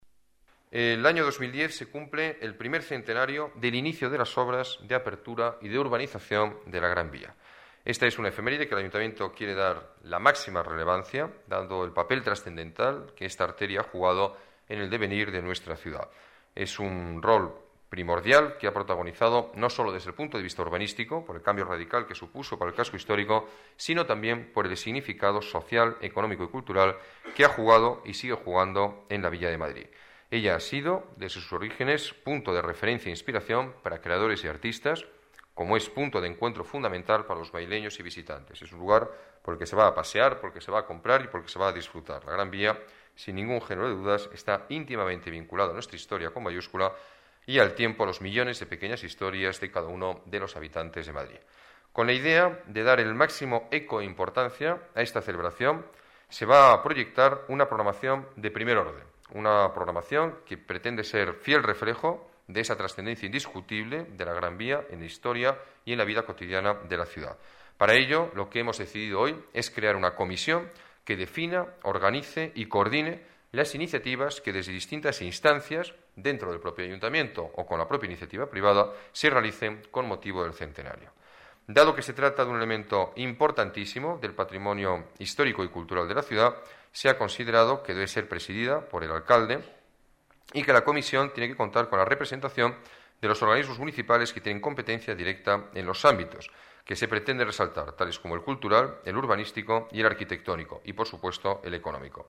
Nueva ventana:Declaraciones del alcalde de Madrid, Alberto Ruiz-Gallardón: Centenario Gran Vía